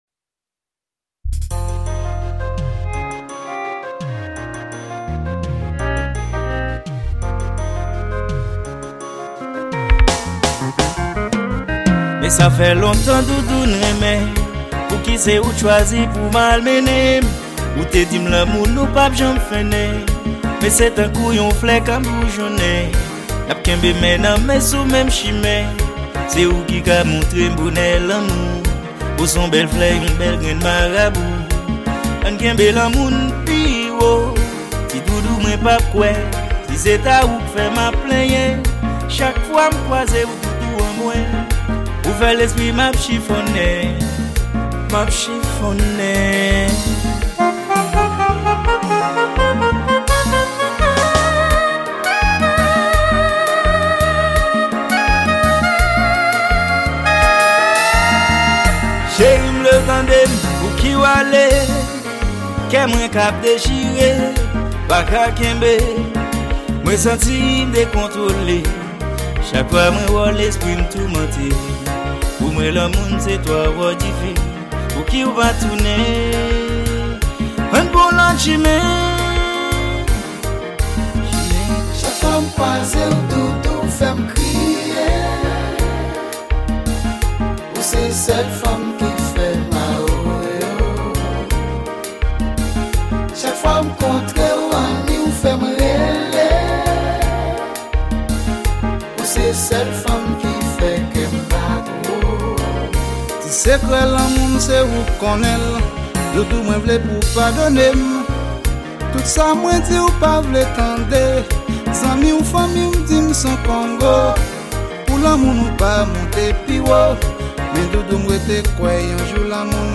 Genre : KONPA